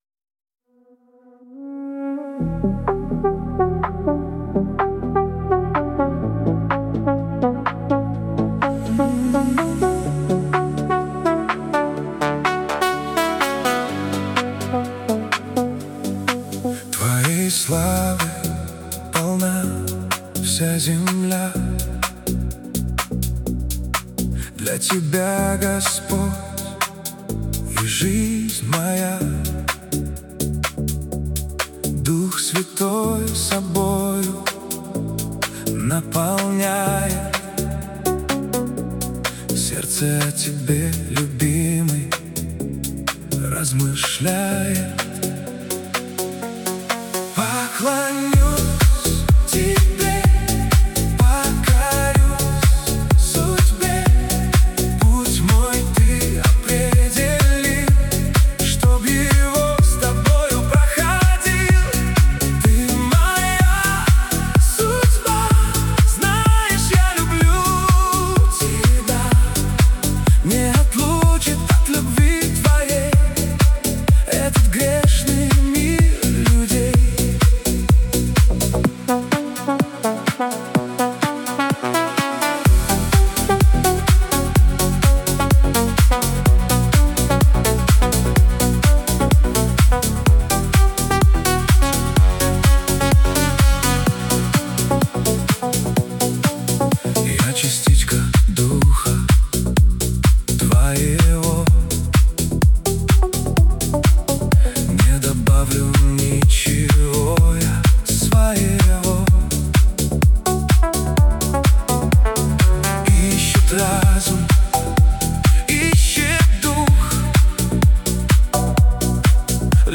песня ai
15 просмотров 45 прослушиваний 3 скачивания BPM: 126